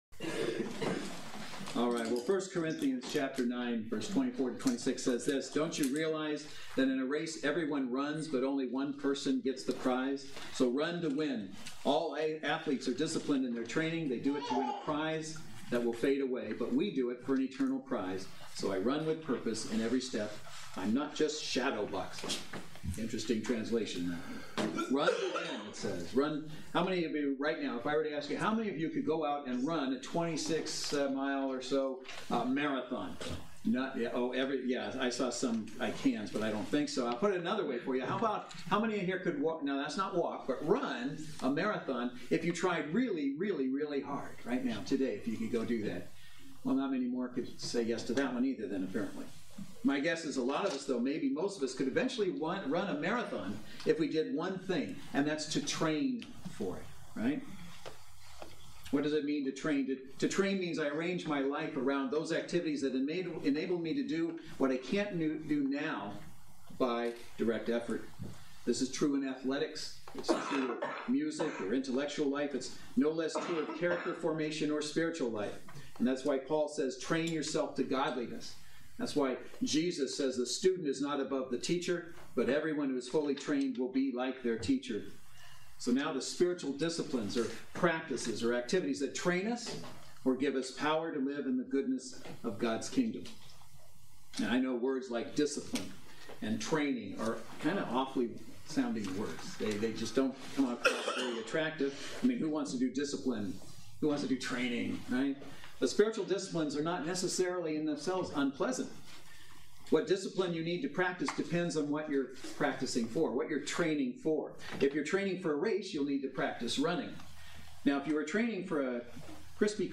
Series: Spiritual Disciplines: Tools in our Toolbox Service Type: Saturday Worship Service